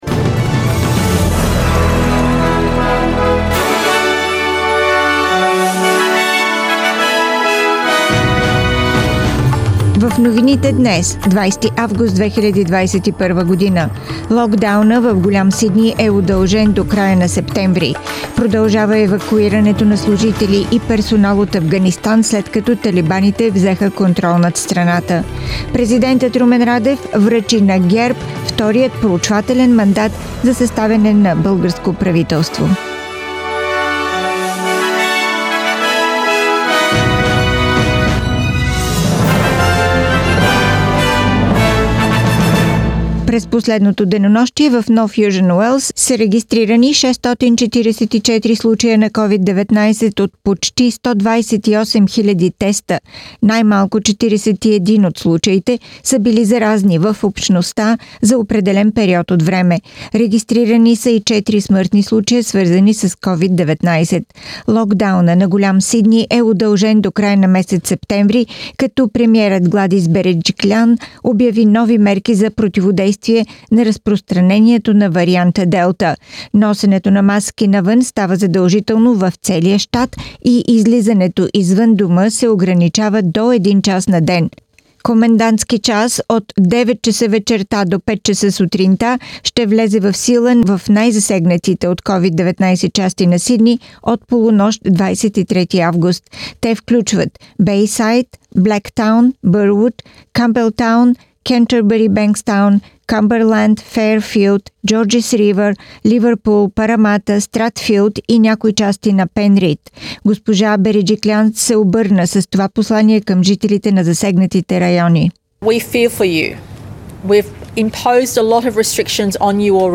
Weekly Bulgarian News – 20th August 2021